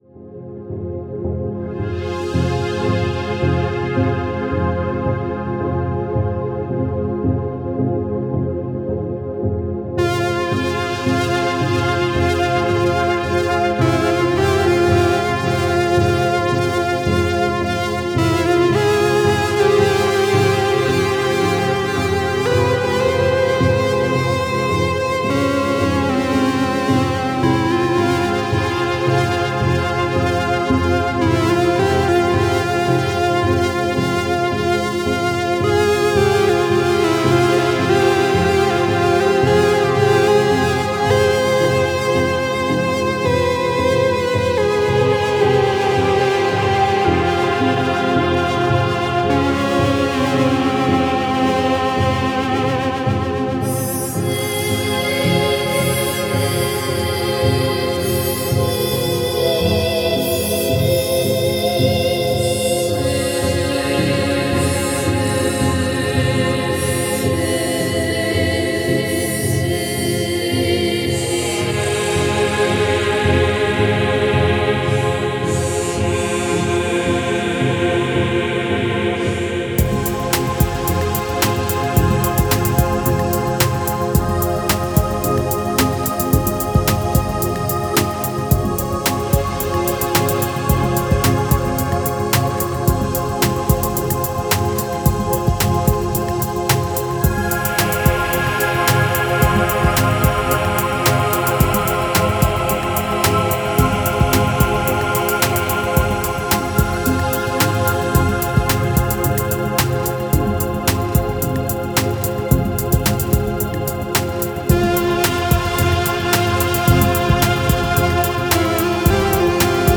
Musik für atmosphärische Abendstunden!
Synthesizersounds zum Relaxen!